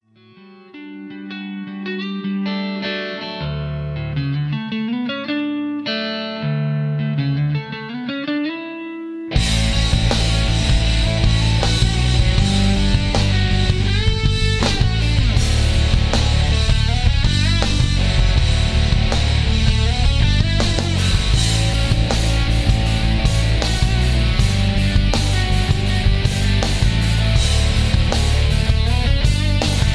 karaoke
rock